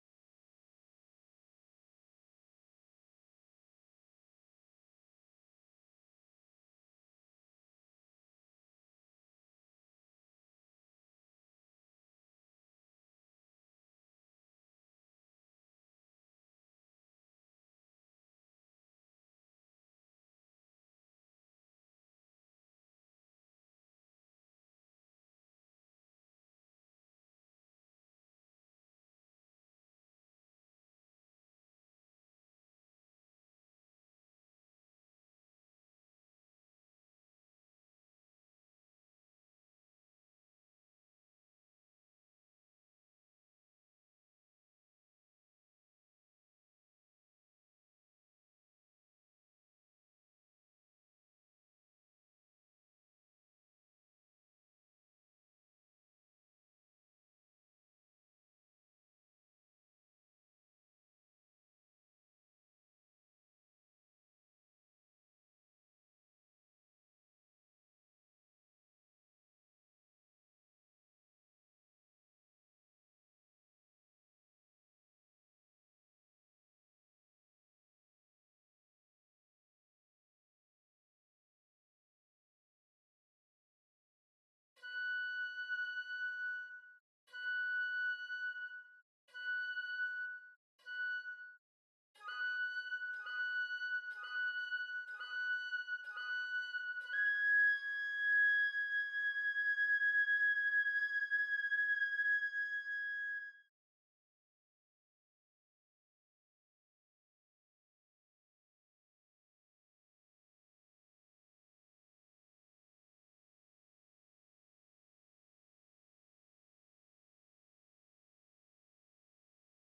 2. Flute (Flute/Normal)
Holst-Mars-30-Flute_1.mp3